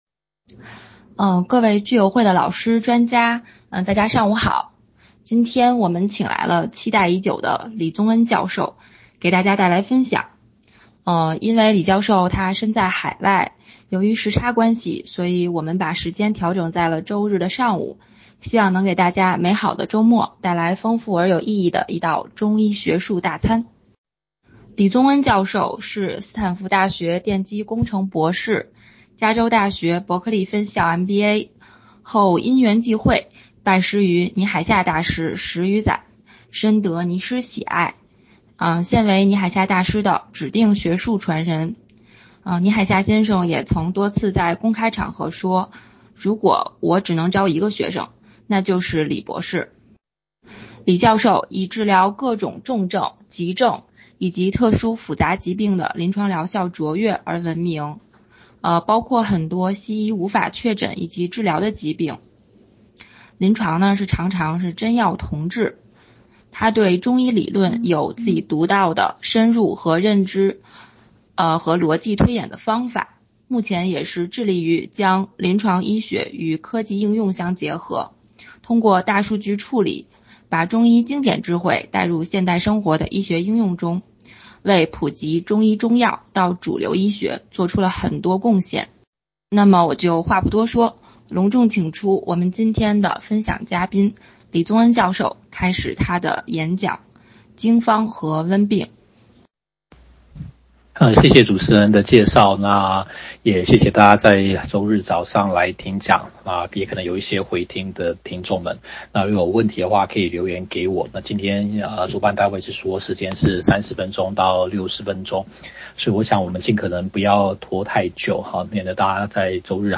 網上講座：經方和溫病 上周末應北京中醫醫院的一個聚友會邀請，做了一次網上講座，簡單討論了經方和溫病的歷史及觀點異同。
而這次使用的軟件是微信，一段話不能超過一分鐘。錄音檔是主辦單位幫忙把每段話連接在一起，每近一分鐘可能會聽到一些不自然的轉折。